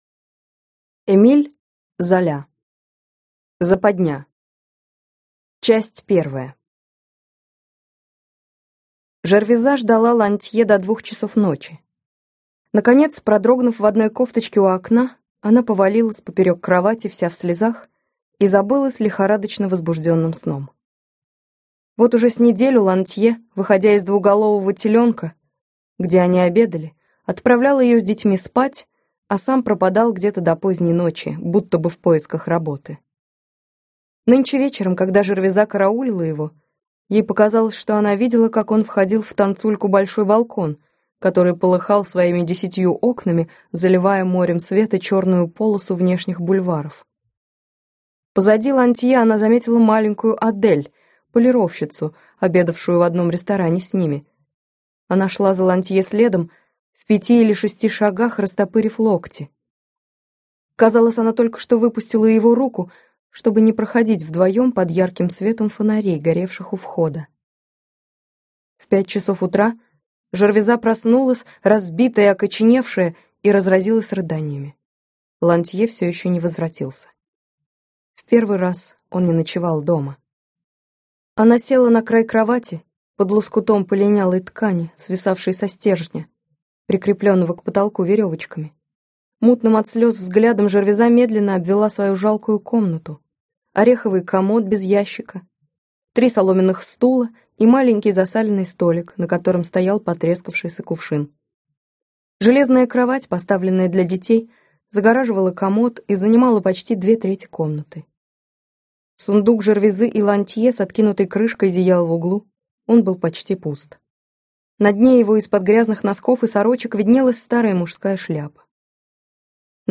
Аудиокнига Западня - купить, скачать и слушать онлайн | КнигоПоиск